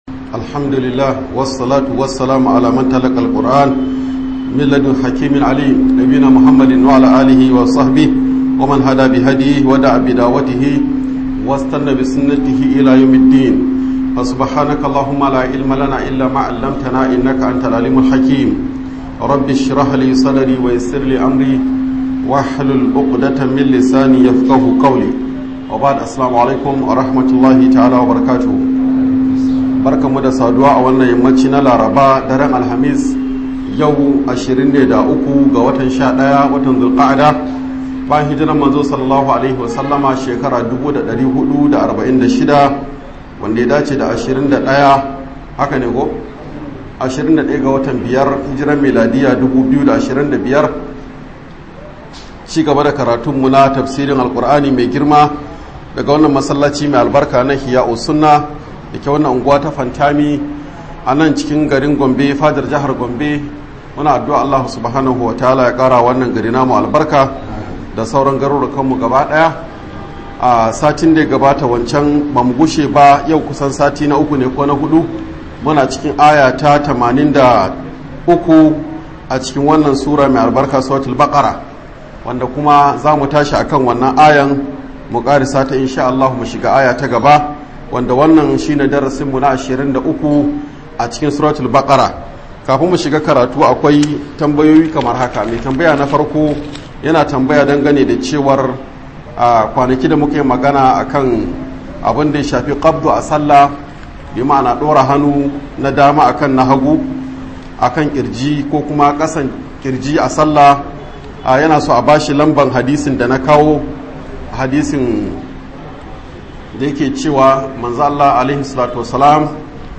Tafseer Suratul Baqarah